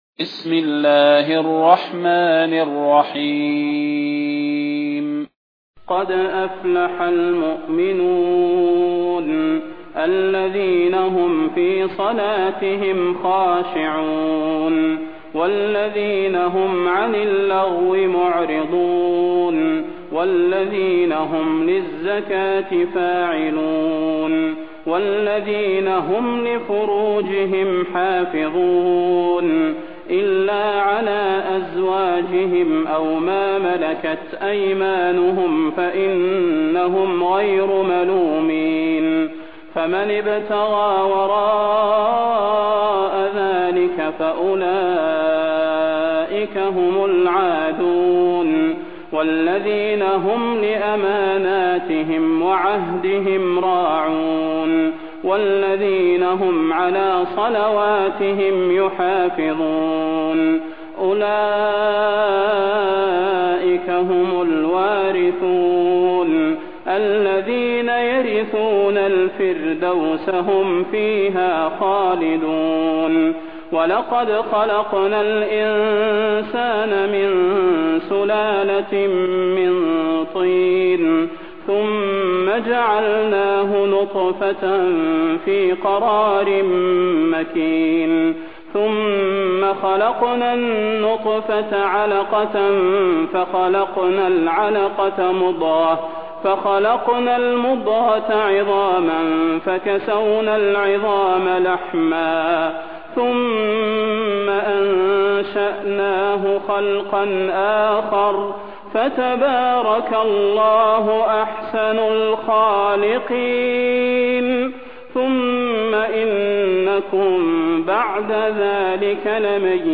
المكان: المسجد النبوي الشيخ: فضيلة الشيخ د. صلاح بن محمد البدير فضيلة الشيخ د. صلاح بن محمد البدير المؤمنون